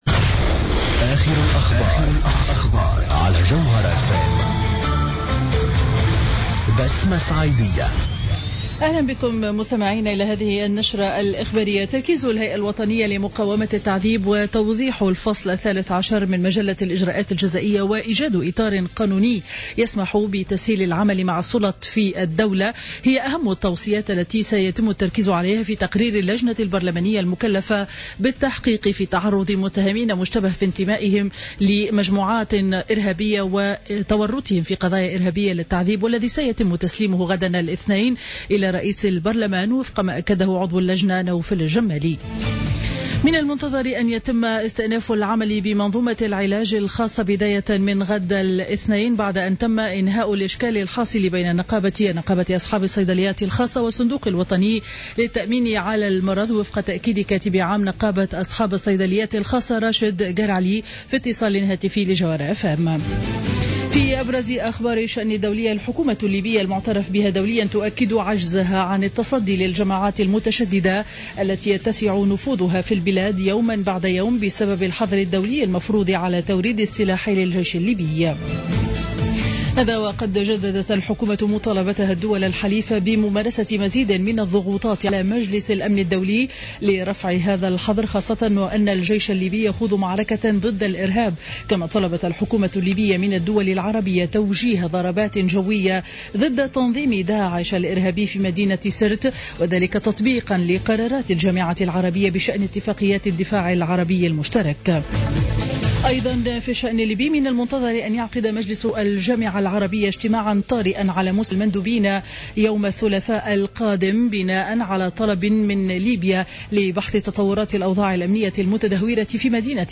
نشرة أخبار منتصف النهار ليوم الأحد 16 أوت 2015